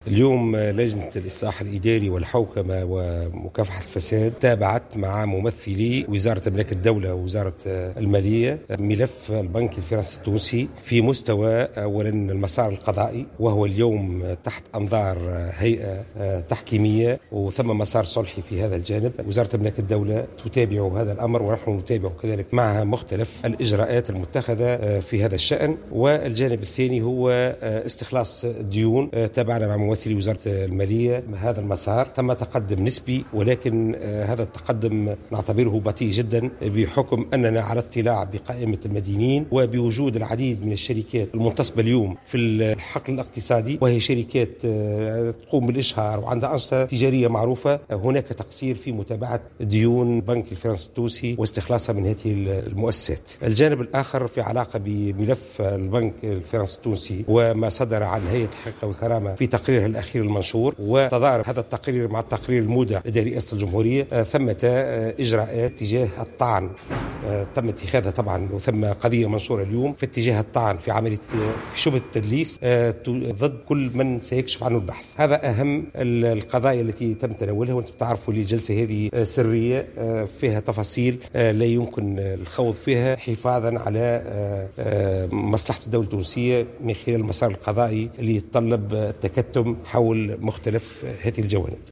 أكد رئيس لجنة الإصلاح الإداري و الحوكمة و مقاومة الفساد بدر الدين القمودي في تصريح لمراسلة الجوهرة "اف ام" أن المسار القضائي في تواصل حول مسألة البنك الفرنسي التونسي .